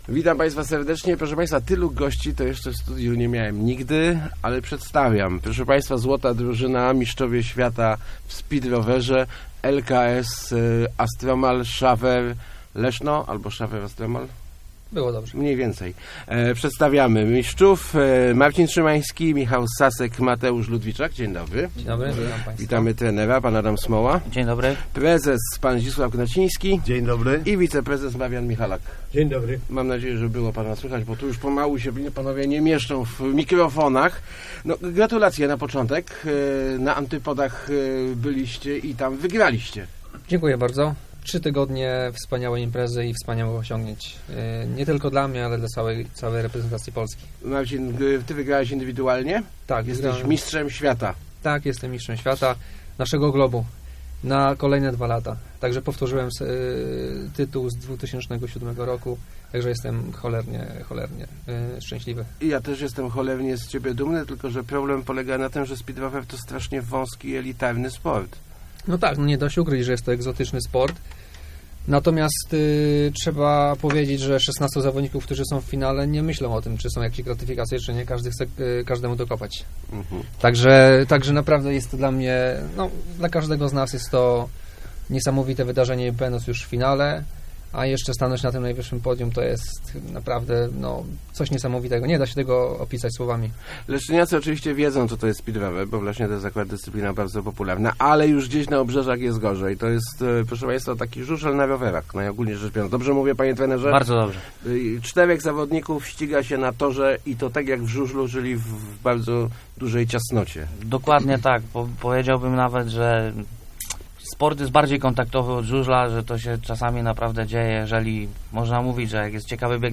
Niewiele brakowało, a nie pojechalibyśmy na te Mistrzostwa Świata, na szczęście wspomógł nas samorząd Leszna - mówili w Rozmowach Elki zawodnicy i działacze LKS Astromal Szawer Leszno.